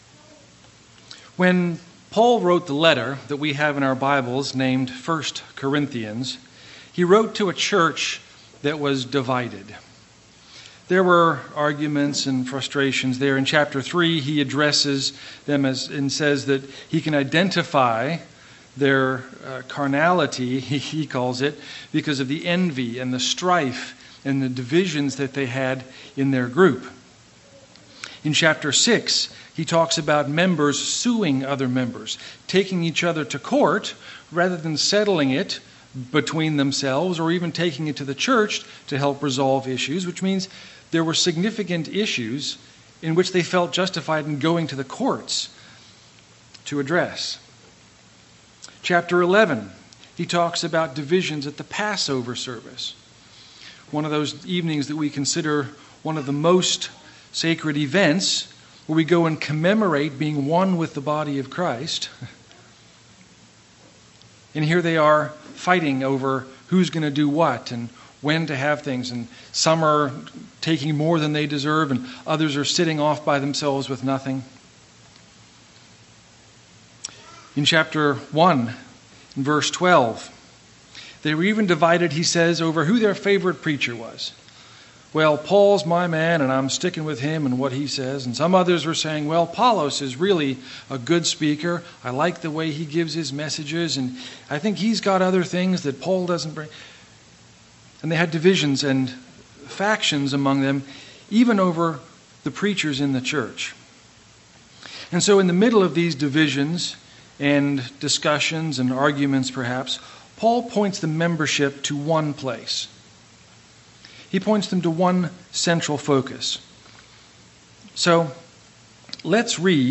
Sermons
Given in Lawton, OK